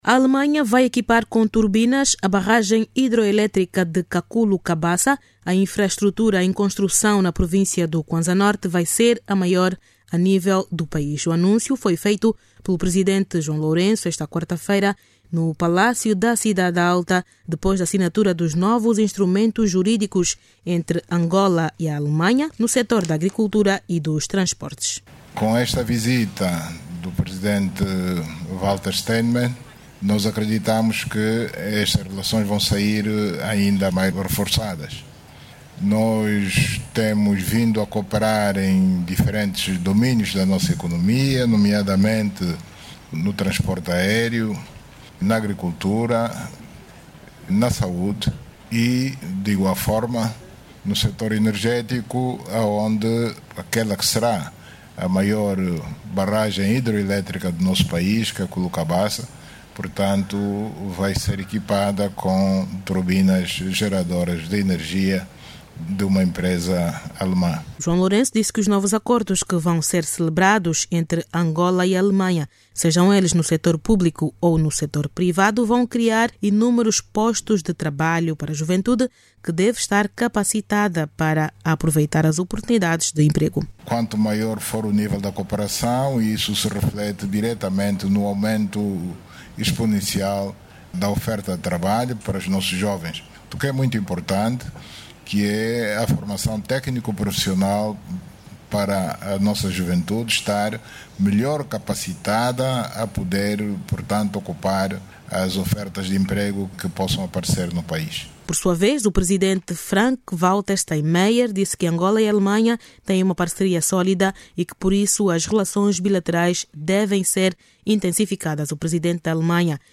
Saiba mais dados no áudio abaixo com a repórter